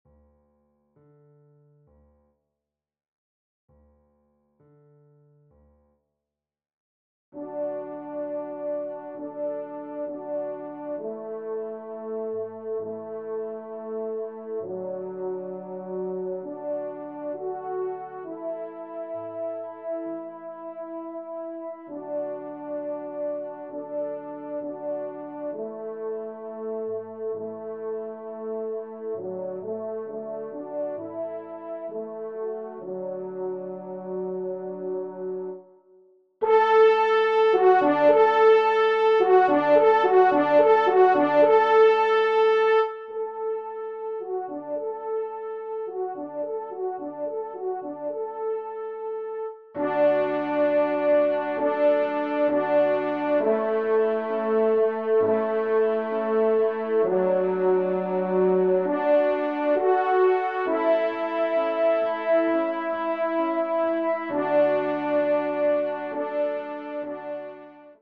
Genre :  Divertissement pour Trompe ou Cor et Piano
2e Trompe